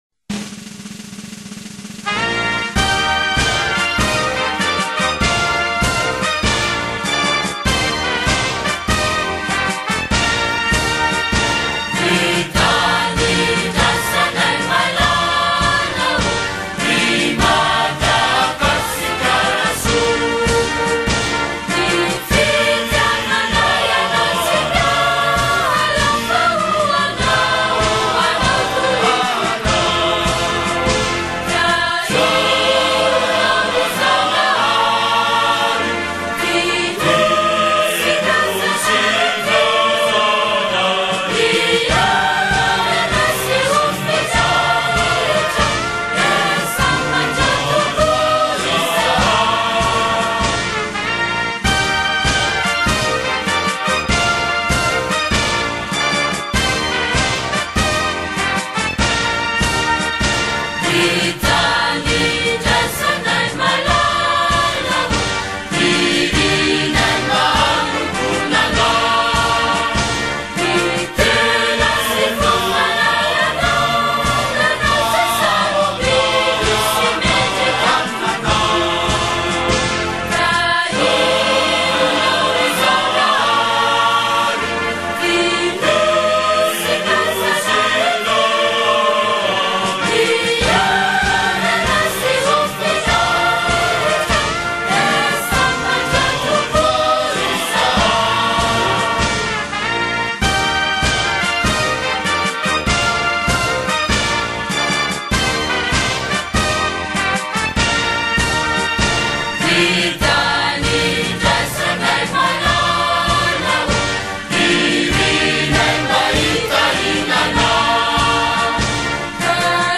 торжественная мелодия